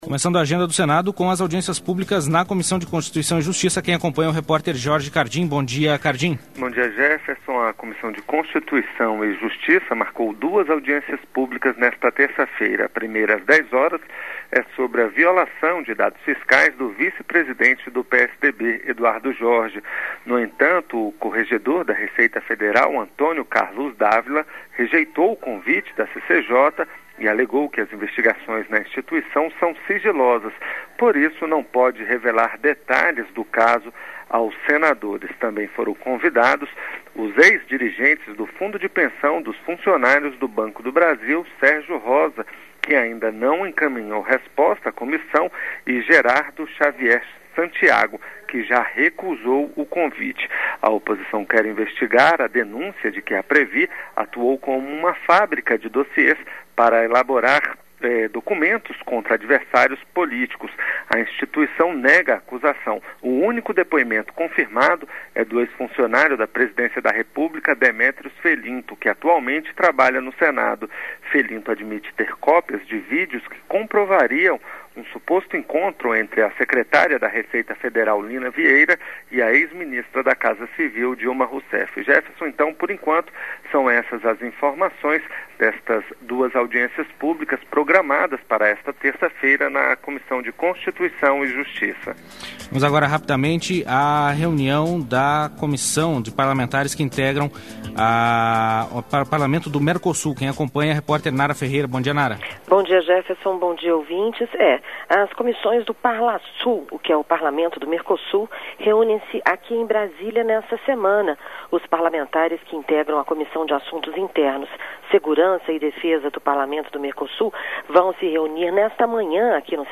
Giro de notícias com os repórteres da Rádio Senado.